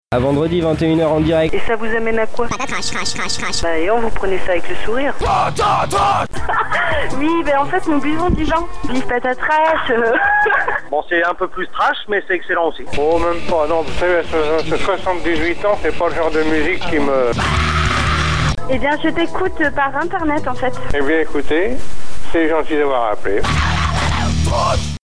Jingle des deux ans
(avec toi ! merci de vos appel)